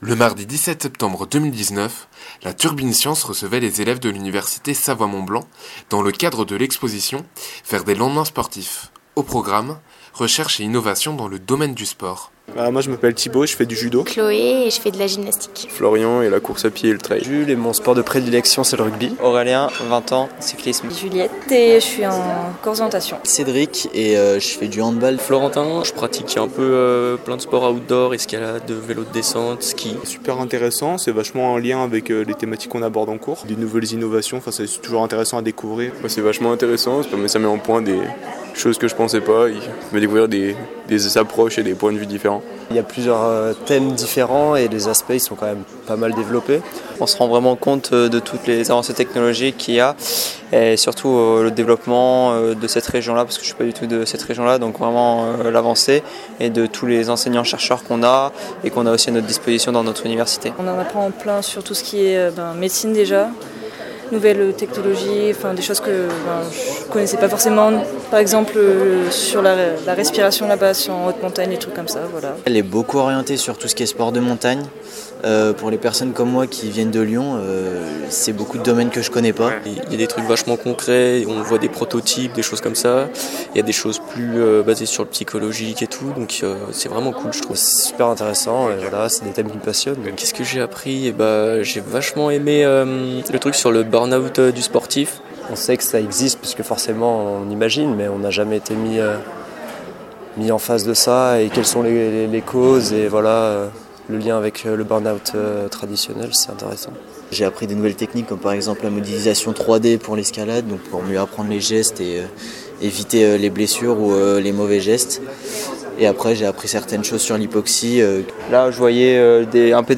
Un Raid qui a pris place cette année au bord du lac, sur la plage des Marquisas, et également dans la vieille ville d’Annecy.
micro-trottoir.mp3